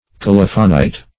Search Result for " colophonite" : The Collaborative International Dictionary of English v.0.48: Colophonite \Col"o*pho*nite\ (k[o^]l"[-o]*f[-o]*n[imac]t or k[-o]*l[o^]f"[-o]*n[imac]t), n. [Cf. F. colophonite.